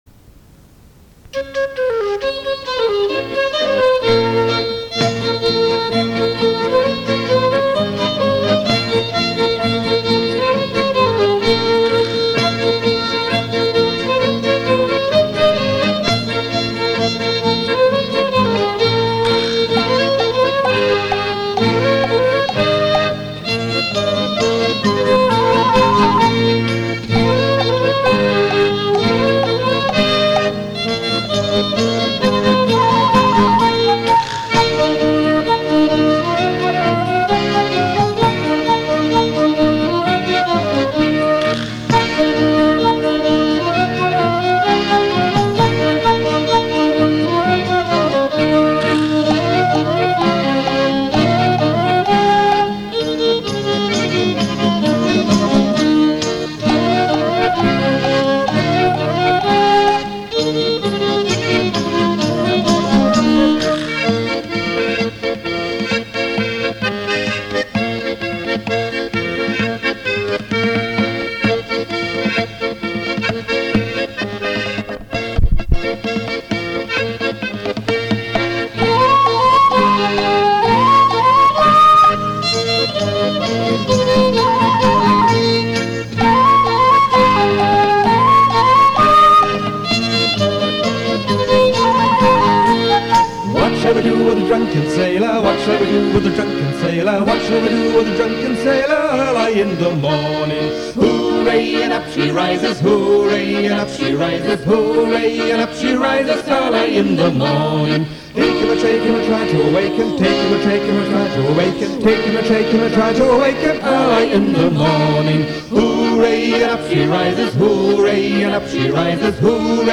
TANZMUSIKEN